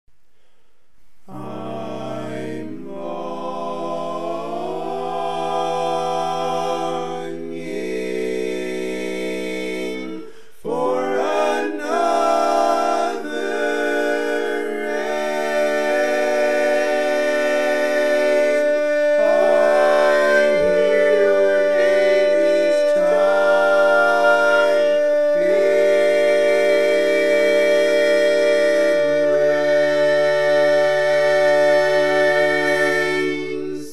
Key written in: E♭ Major
Type: Barbershop
Learning tracks sung by